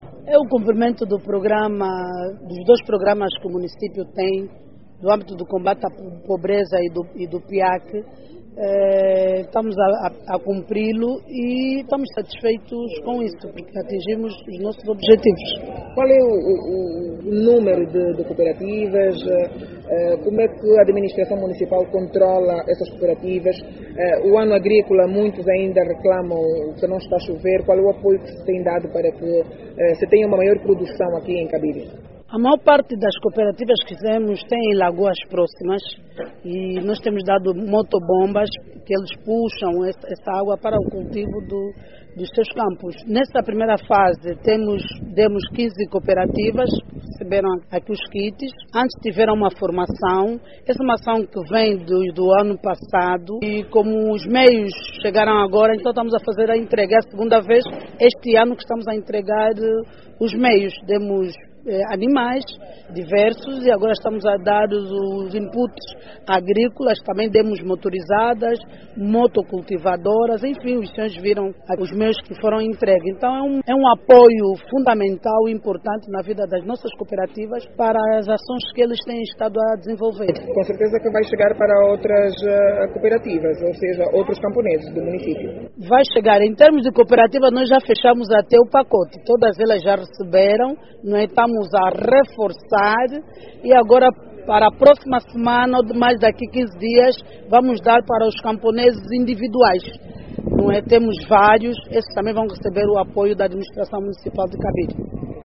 Durante o seu  descurso, a governante garantiu que a Administração Municipal continuará a apoiar os camponeses com sementes e instrumentos agrícolas para mitigar as dificuldades que os mesmos têm encontrado na aquisição destes materiais de forma a aumentarem substancialmente os níveis de produção na presente época agrícola.